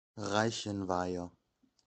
Riquewihr (French pronunciation: [ʁikviʁ] ; Alsatian: Richewihr; German: Reichenweier [ˈʁaɪçn̩vaɪɐ]
De-Reichenweier.ogg.mp3